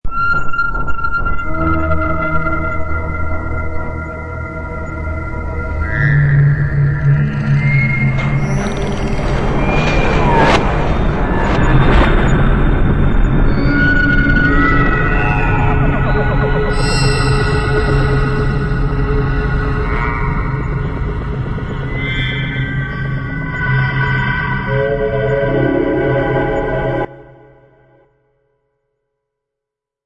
Scary Effect Sound Button - Free Download & Play